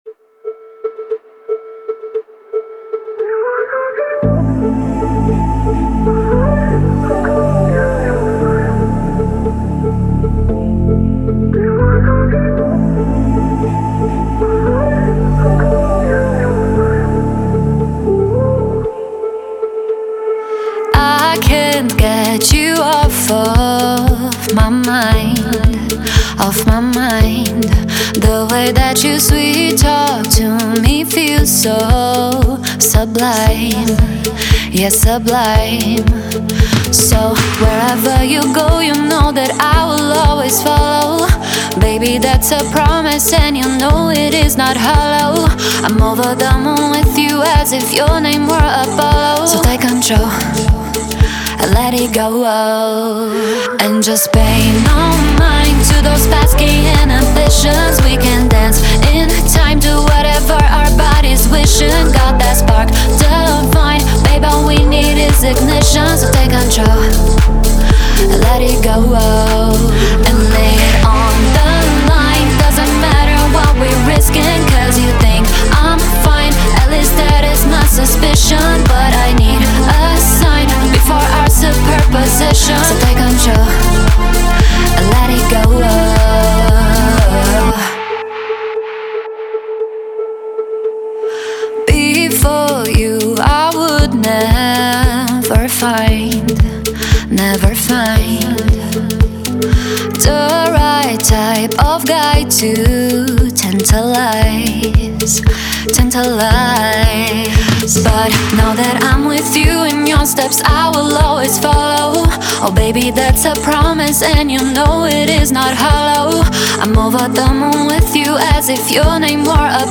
Electronic